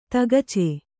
♪ tagace